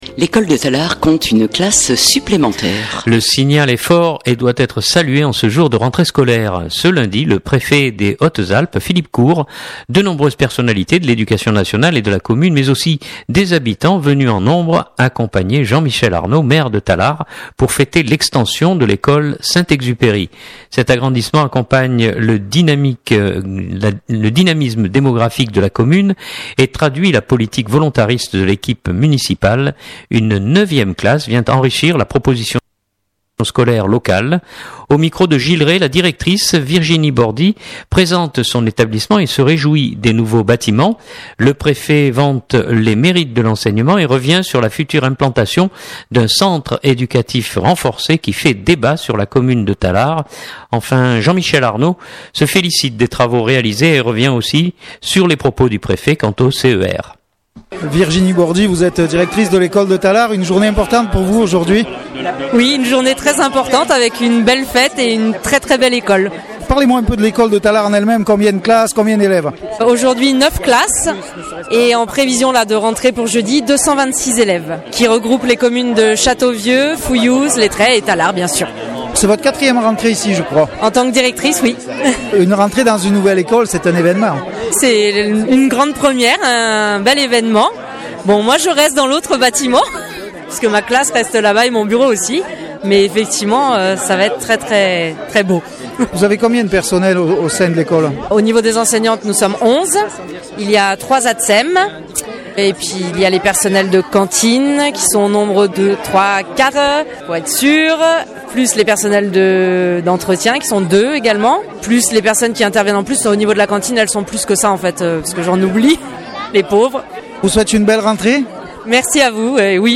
Ce lundi, le Préfet des Hautes-Alpes Philippe Court, de nombreuses personnalités de l’Education Nationale et de la commune mais aussi des habitants venus en nombre accompagnaient Jean-Michel Arnaud Maire de Tallard pour fêter l’extension de l’école St-Exupéry.
Le Préfet vante les mérites de l’enseignement et revient sur la future implantation d’un Centre Educatif Renforcé qui fait débat sur la commune de Tallard, enfin Jean-Michel Arnaud se félicite des travaux réalisés et revient sur les propos du préfet quant au CER. 5’38’’ ECOUTER OU TELECHARGER Durée : 06'35'' Lancement tallard.mp3 (6.04 Mo)